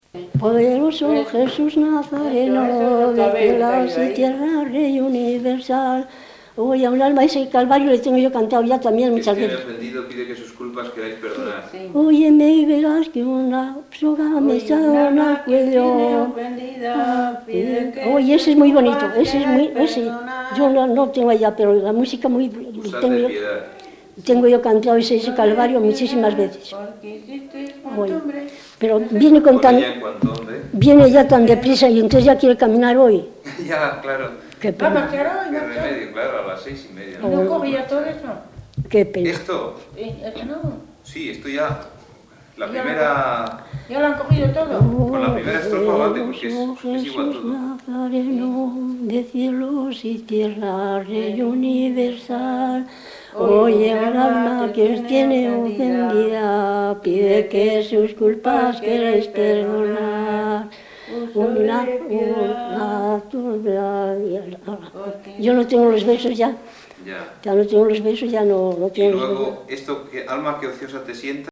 Grabación realizada en Palencia, en 1979.
Género / forma: Canciones populares-Palencia (Provincia) Icono con lupa
Canciones populares Icono con lupa